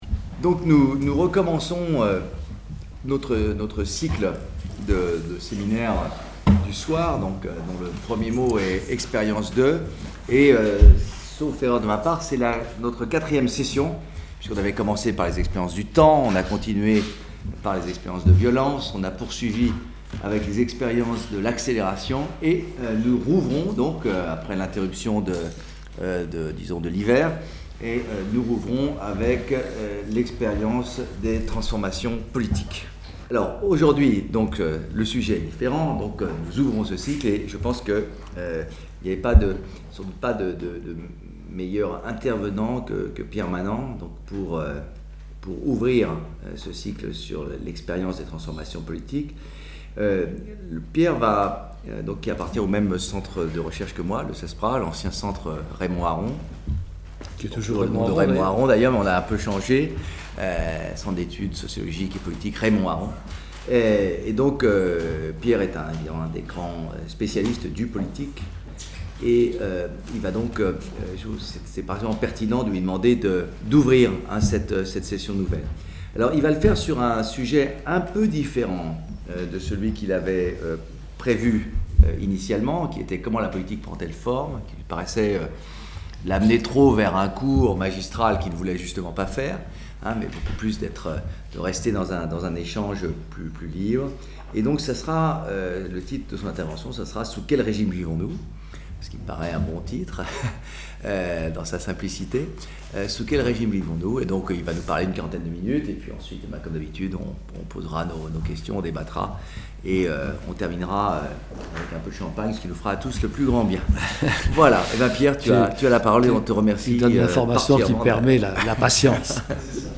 Séminaire dispensé par Pierre Manent Organisé en collaboration avec le labex Tepsis et le concours de l'Institut CDC pour la Recherche